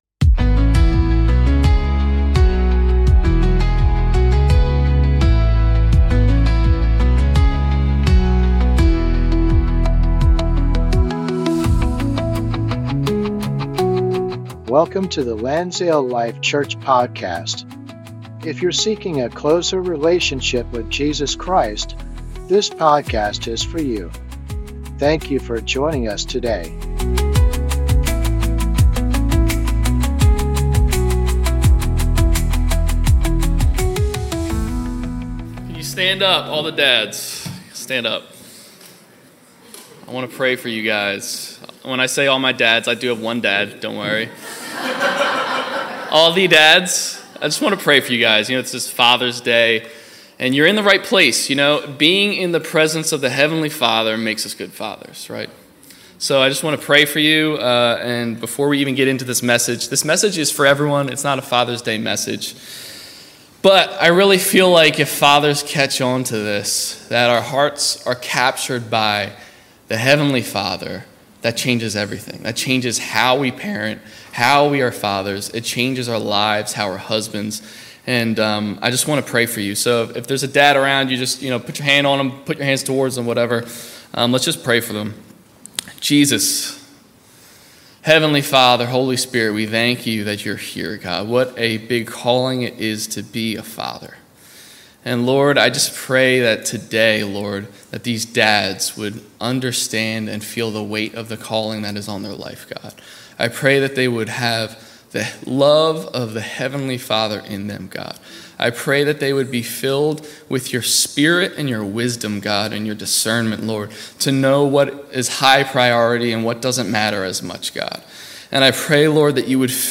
Sunday Service - 2025-06-15